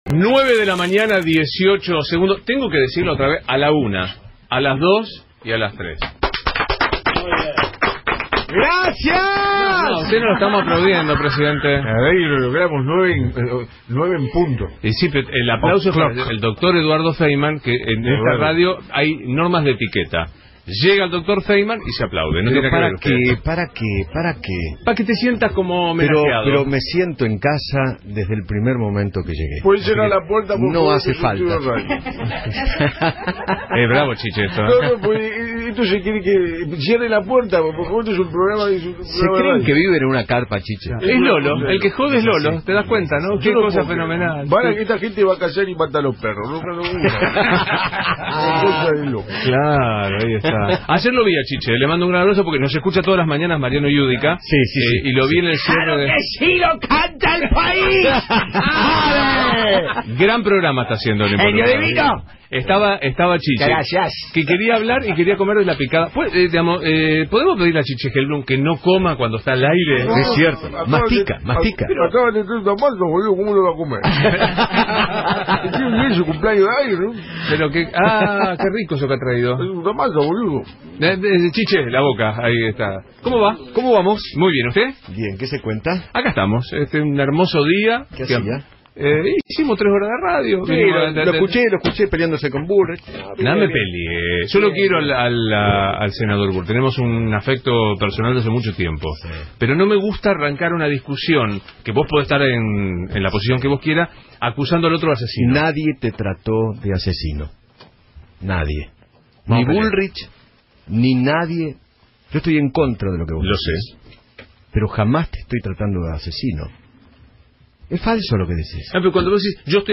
Abogados ambos, los conductores protagonizaron una discusión técnica que anticipa lo que sucederá en el Congreso de la Nación.